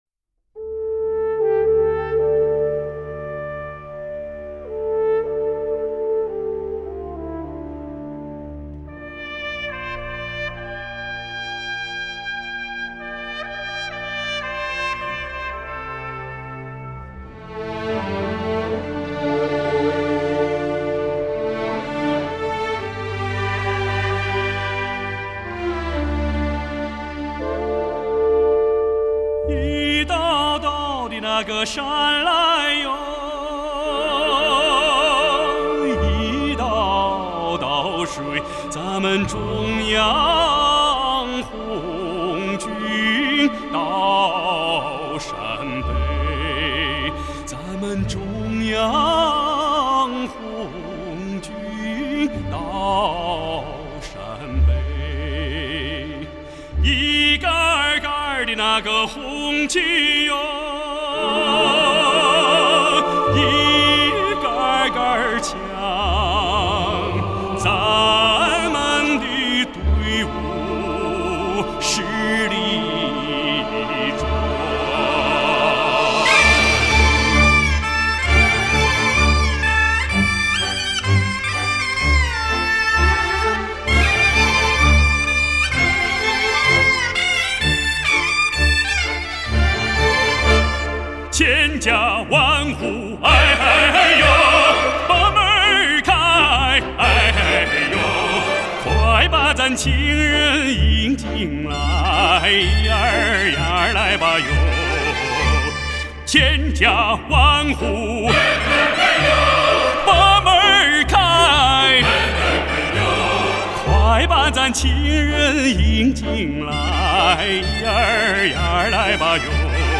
●崭新唱片载体“黑胶王”灌录，兼具黑胶唱片之厚润音色与CD唱片之高信噪比，音效发烧；